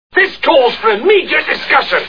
Monty Python's Life of Brian Movie Sound Bites